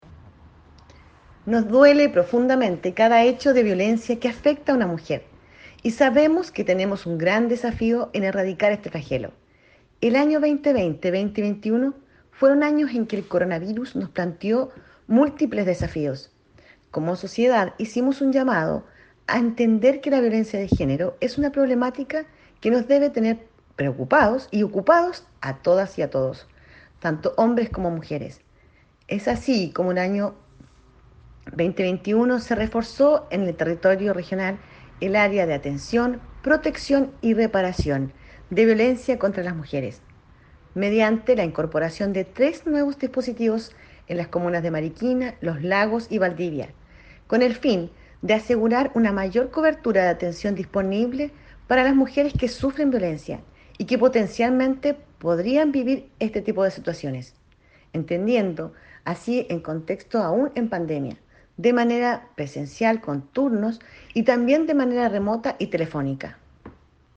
REPORTAJE-CUÑA-01-DIRECTORA-SERNAMEG-Unidad-de-Violencia-contra-la-Mujer.mp3